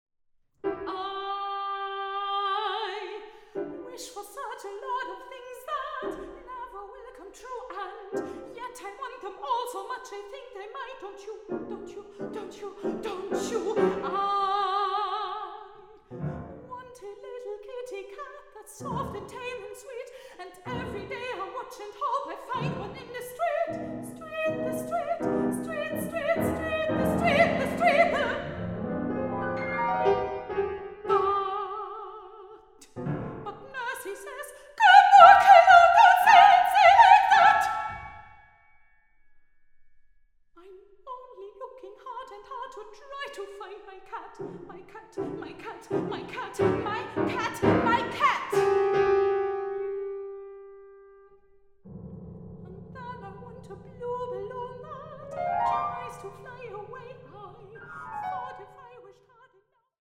Sopran
Klarinette
Klavier